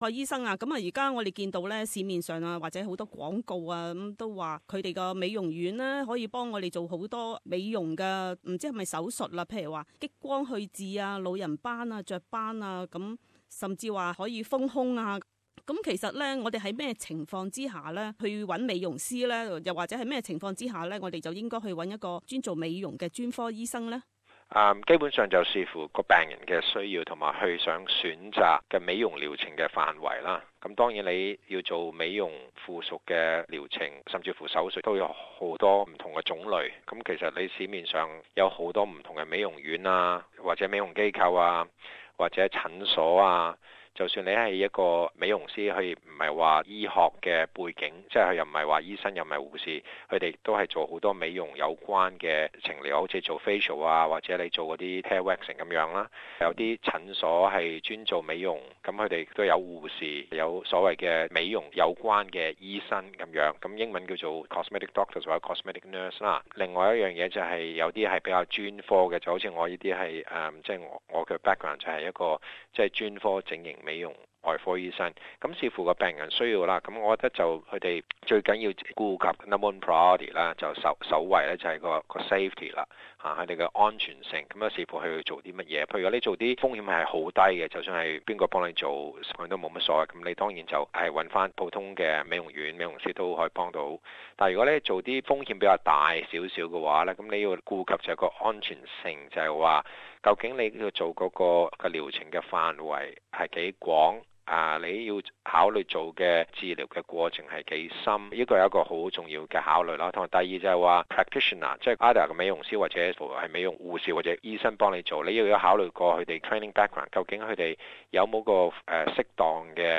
醫生話你知：整形美容外科醫生專訪